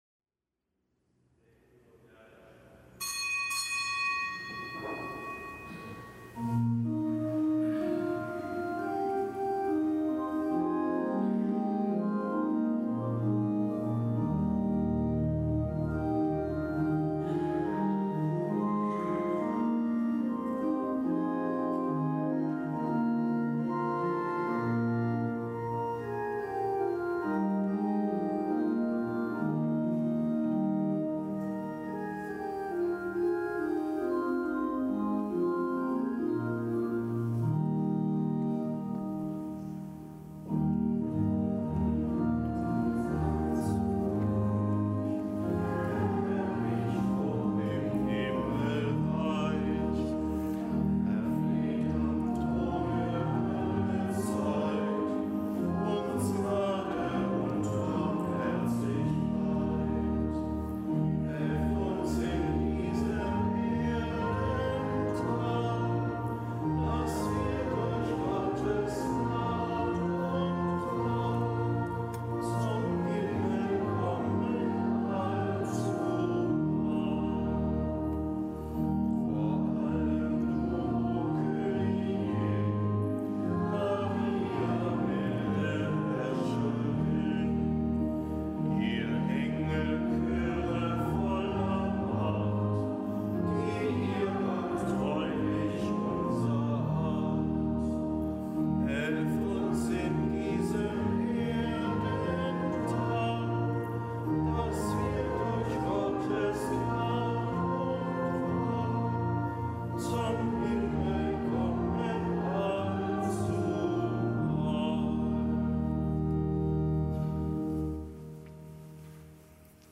Kapitelsmesse aus dem Kölner Dom am Fest der Erzengel Michael, Gabriel und Rafael.